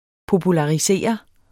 Udtale [ pobulɑiˈseˀʌ ]